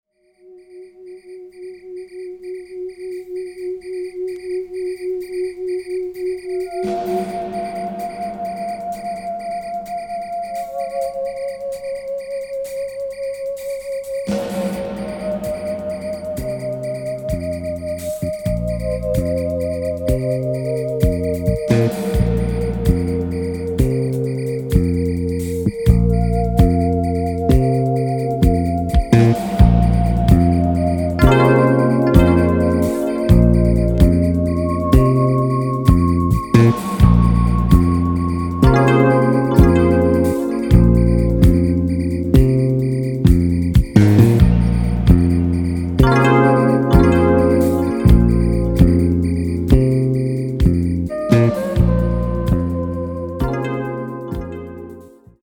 キーワード：ミニマル